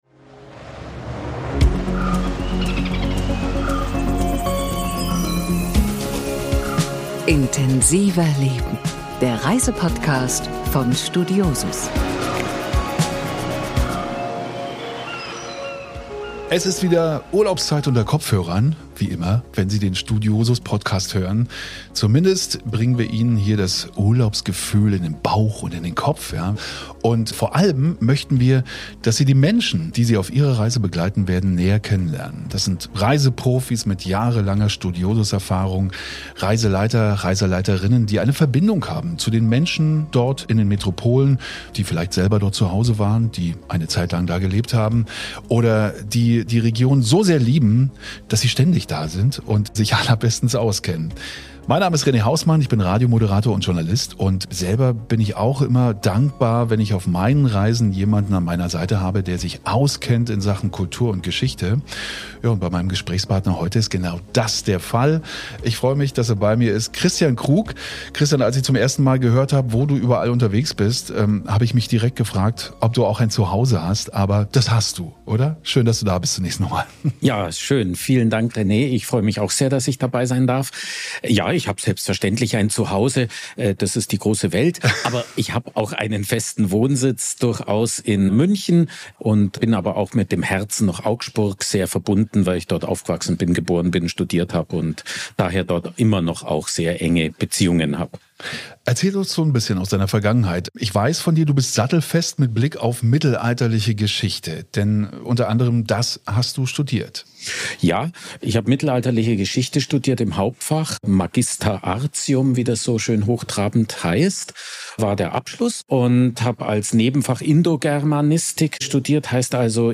Beschreibung vor 11 Monaten Der Studiosus-Podcast „Intensiverleben“ wird zu einem echten Farbenmeer, denn es geht nach Kolkata, auch bekannt als Kalkutta, – kulturelles Herz Indiens, Cricket-Hochburg und Wirkungsort von Mutter Teresa. Im Gespräch